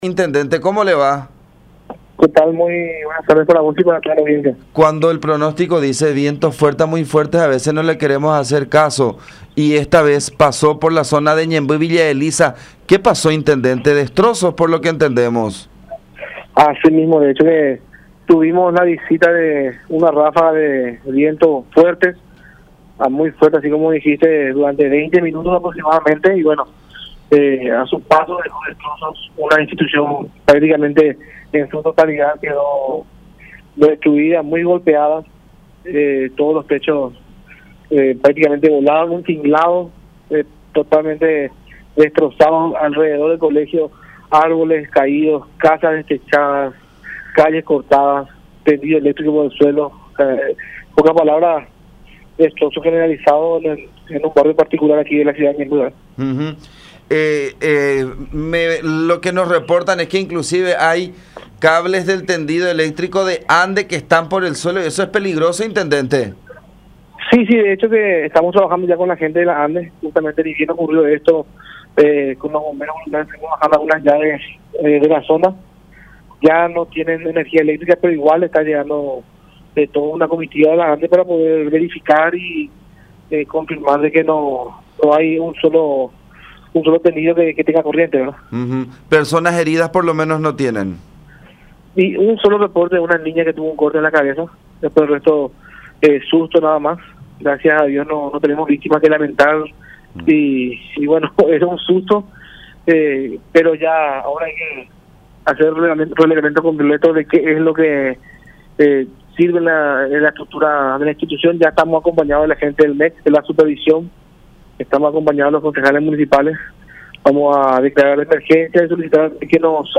32-Lucas-Lanzoni-Intendente-de-Ñemby.mp3